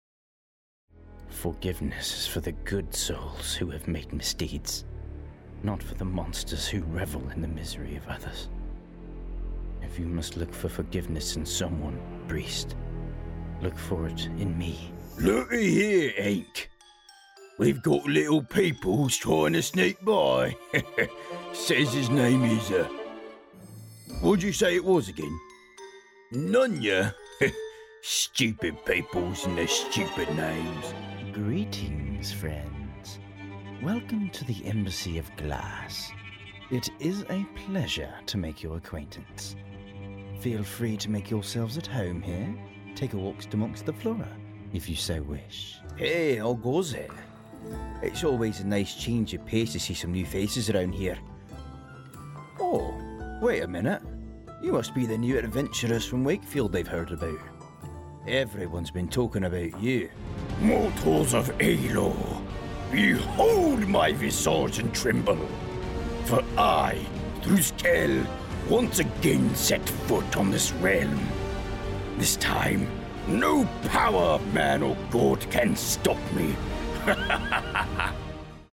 Warm, dark, edgy, and I can switch between accents like a chameleon changes colors.
Video Game Character Demo #1